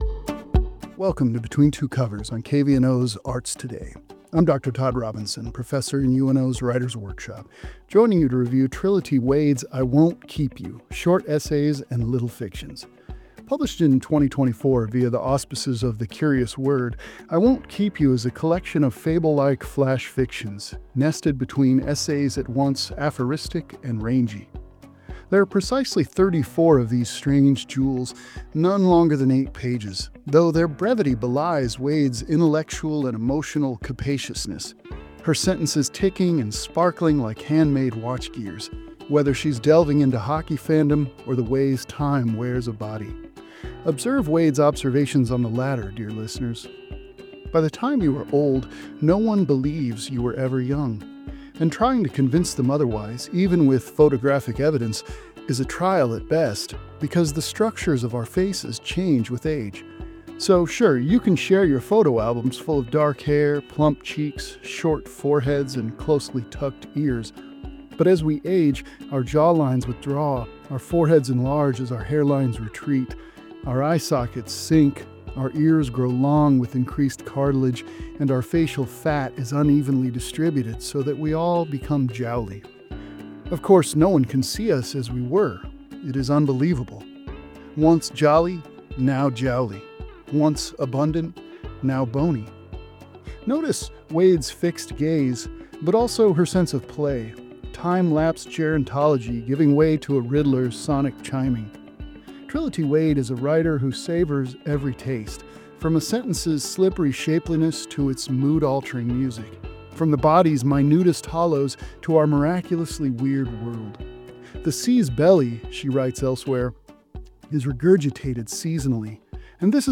The segment, which airs regularly on KVNO’s Arts Today, features voices from Omaha’s literary community sharing titles that inspire and engage readers.